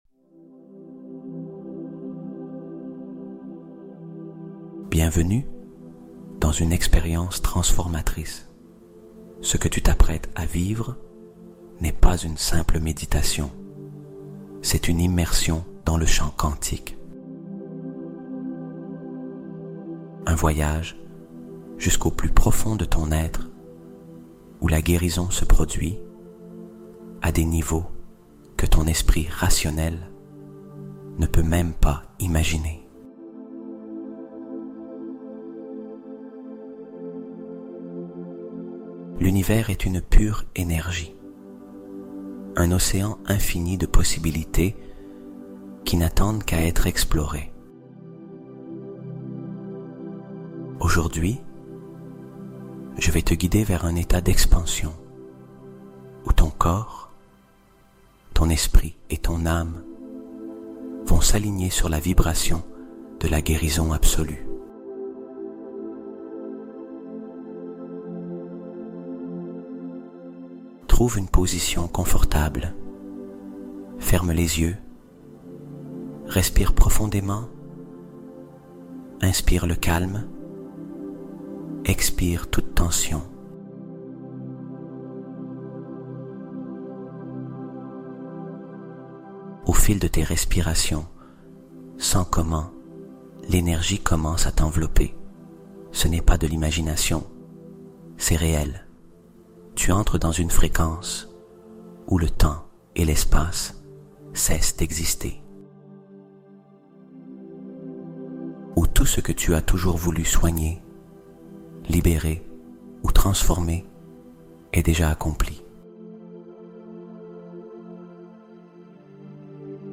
Hypnose Quantique de Guérison : Connecte-toi Au Pouvoir Infini de l'Univers (Sans Coupures)